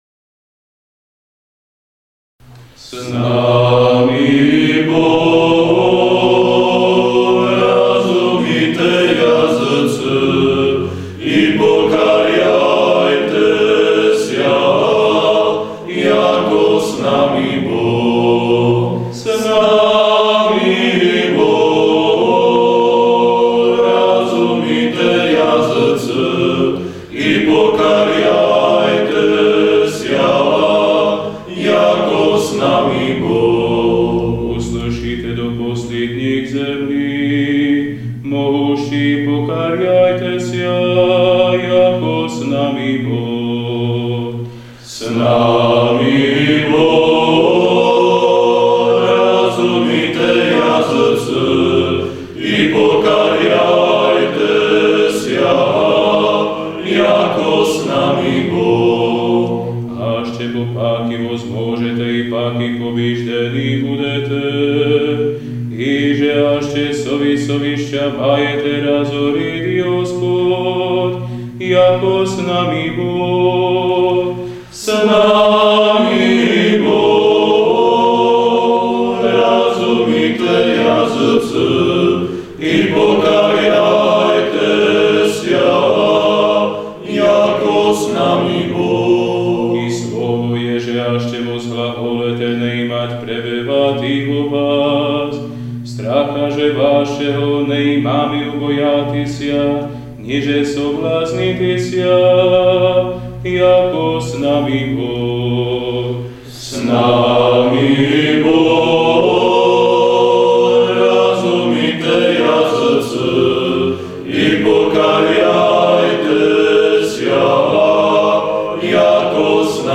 The melodies are alternated in this way through the end of the hymn.
Here is a recording sung by the Prešov Seminary choir, showing the use of the two different melodies.